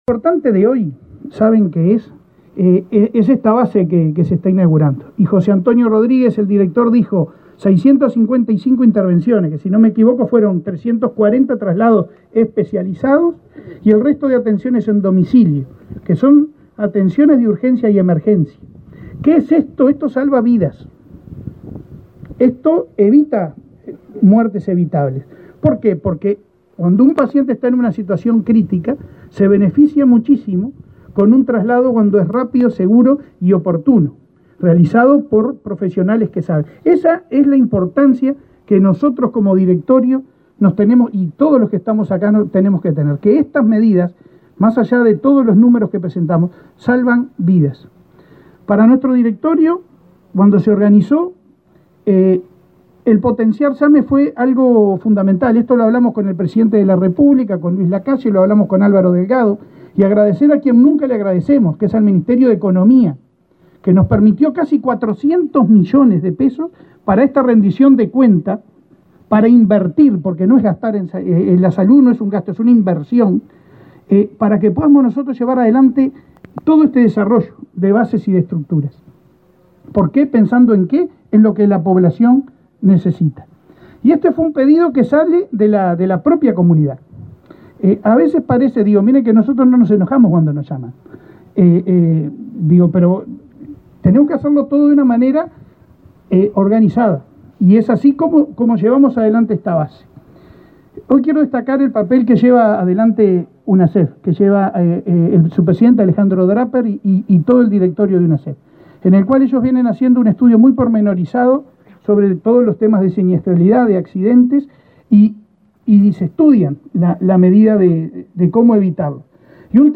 Palabra de autoridades en inauguración de ASSE en San José
El presidente del prestador público, Leonardo Cipriani; el ministro de Transporte, José Luis Falero, y el secretario de Presidencia, Álvaro Delgado, destacaron la importancia de este nuevo servicio.